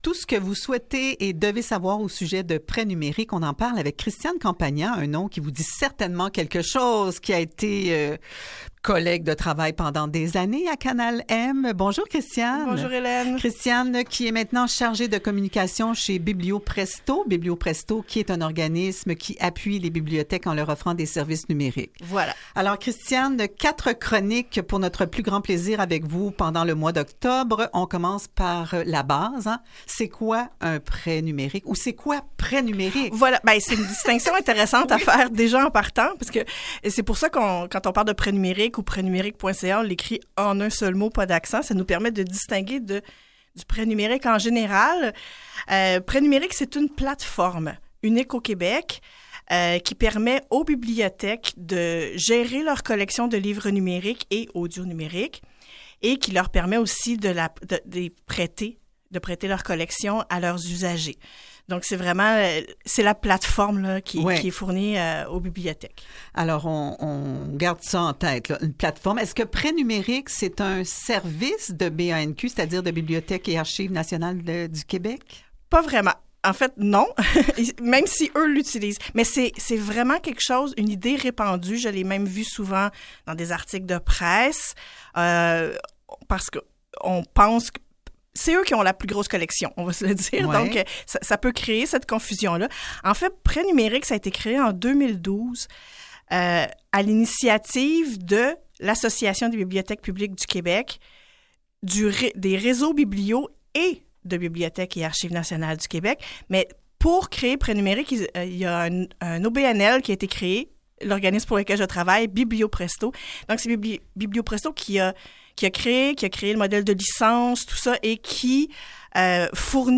Entrevue sur Pretnumerique, 1re partie